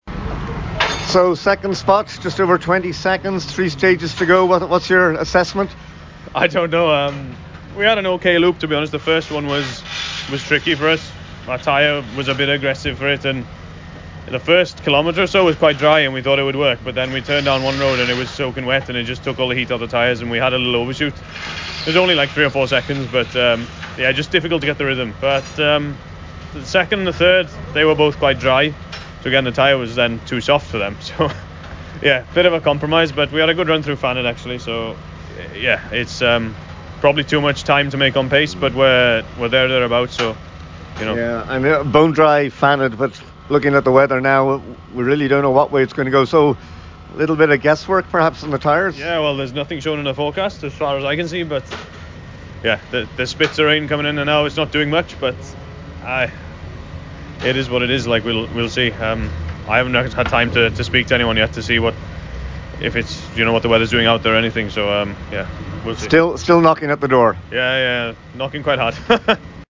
Rally Final Day: Service chats with the leaders